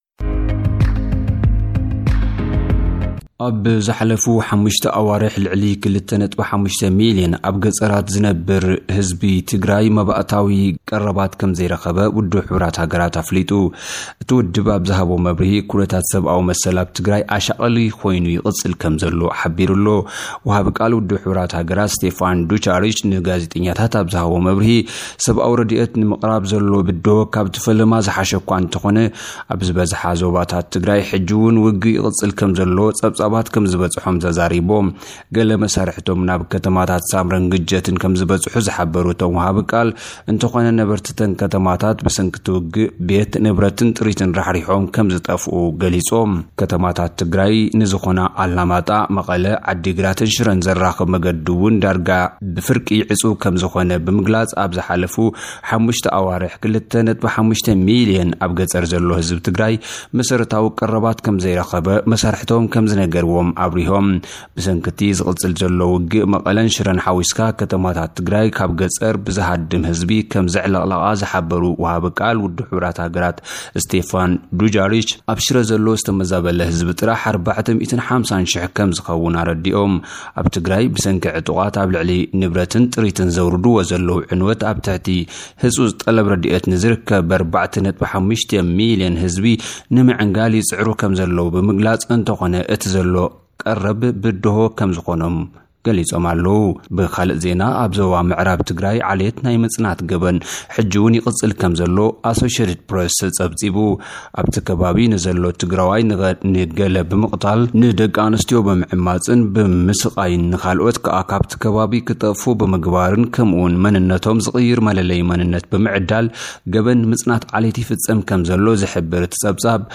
ኣብ ዝሓለፉ 5 ኣዋርሕ 2.5 ሚልዮን ኣብ ገጠራት ትግራይ ዝነብር ህዝቢ ብሰንኪ ኩናት መሰረታውያን ቀረባት ከም ዘይረኸበ ውሕሃገራት ኣፍሊጡ። ኣብ ሞንጎ ክልላት ዓፋርን ሶማልን ብዝተወለዐ ጎንጺ ዛጊት ልዕሊ 100 ሰባት ተቐቲሎም። ሱዳን ኣባላት ዓቀብቲ ሰላም ዝኾኑ ሰራዊት ኢትዮጵያ ካብ ግዝኣታ ክወጹ ሓቲታ። ዝብሉን ካልኦትንዝብሉ ኣርእስታት ጸብጻባት ሉኡኽና እዮም፡ ፡